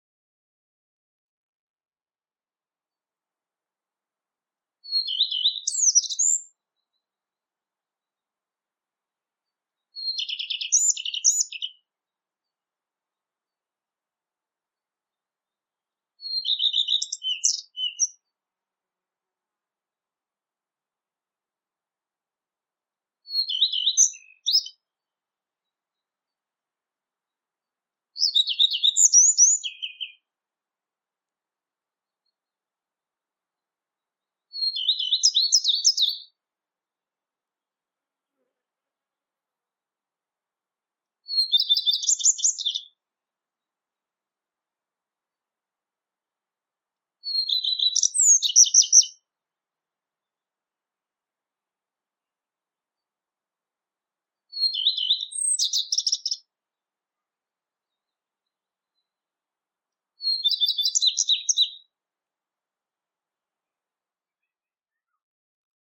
Fågel- och däggdjursläten
Ibland har ljudinspelningen gjorts med kameran.
Rödstjärt   20 juni 2025